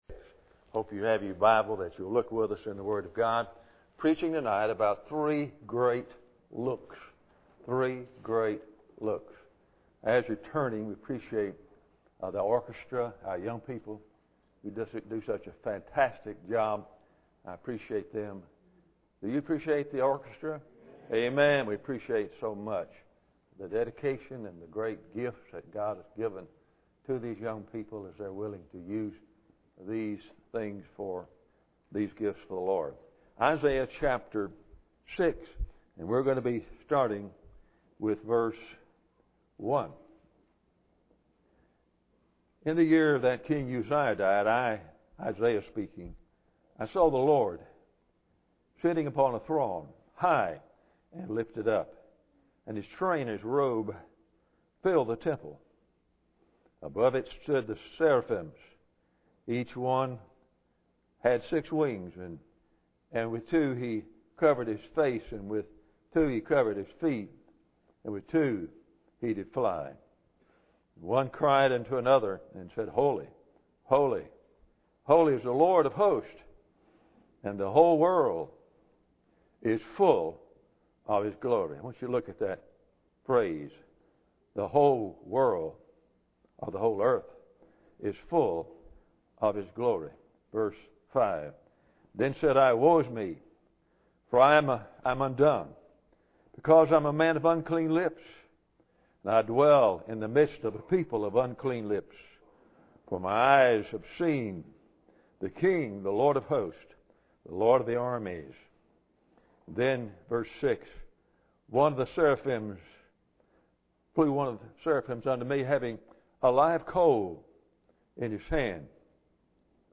In this sermon we will learn more about: 1. The Upward Look 2. The Inward Look 3. The Outward Look
Service Type: Sunday Evening